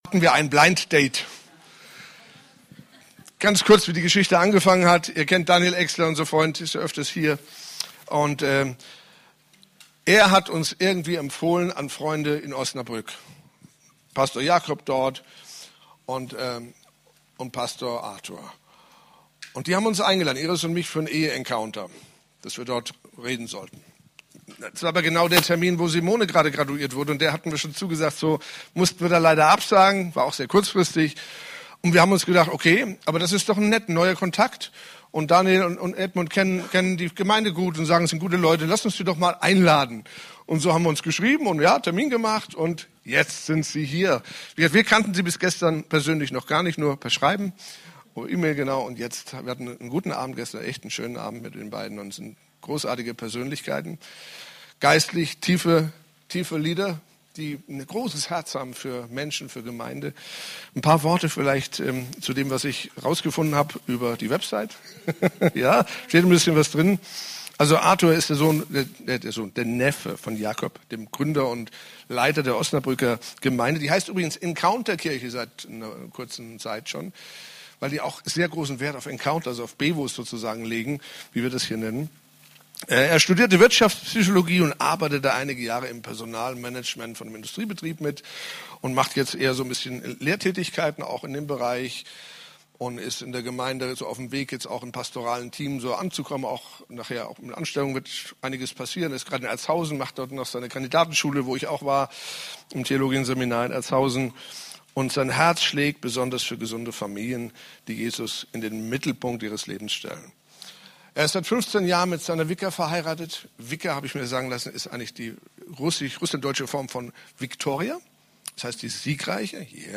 CCLM Predigten Die Kirche muss Kirche bleiben!